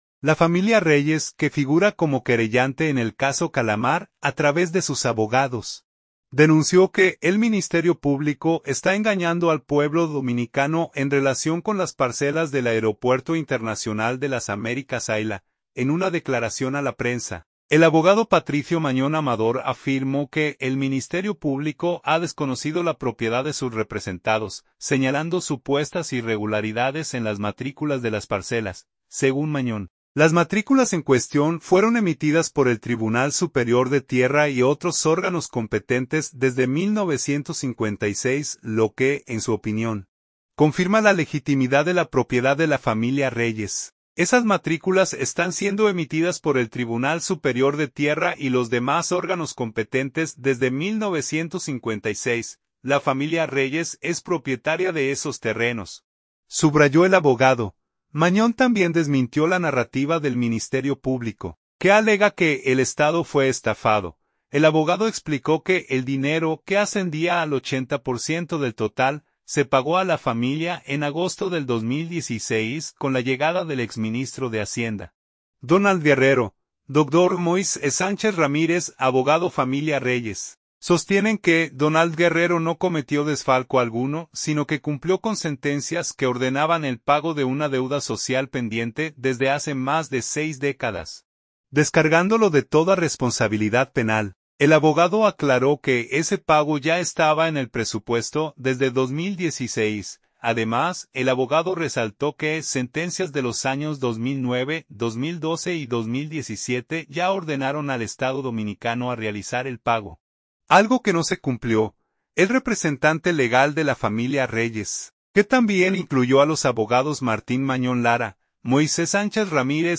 En una declaración a la prensa